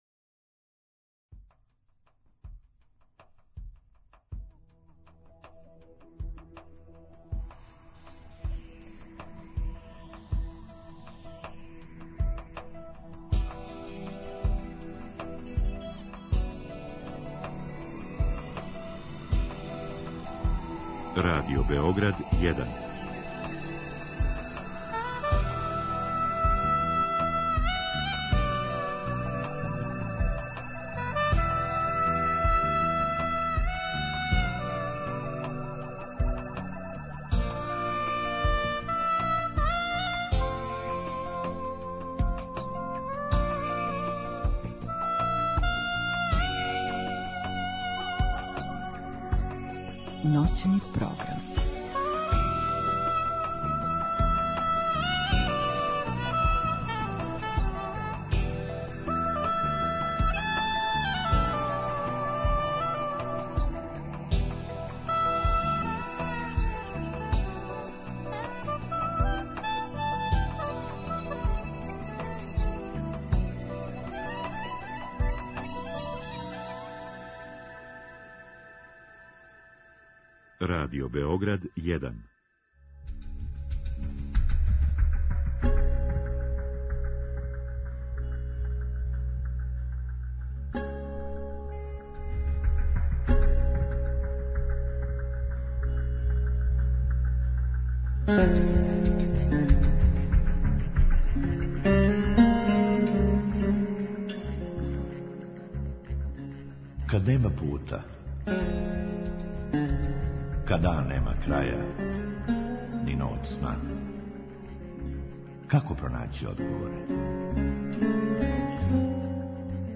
Drugi sat je rezervisan za slušaoce, koji u direktnom programu mogu postaviti pitanje gostu.